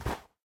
snow2.ogg